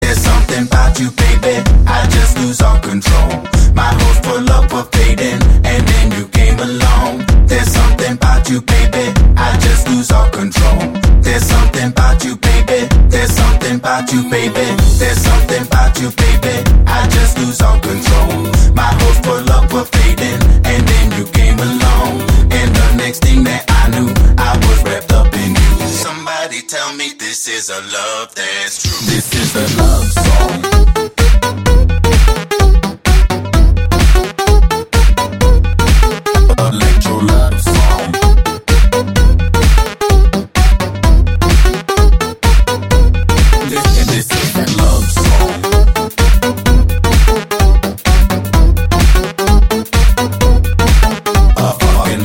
dance
Electronic
клубняк
electro house
Стиль: Electro House